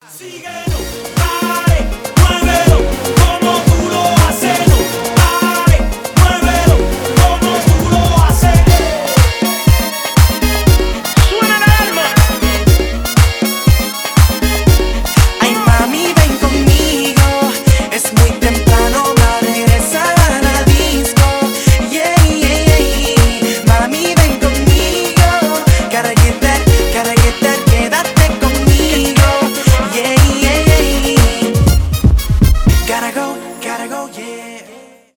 реггетон , зажигательные
танцевальные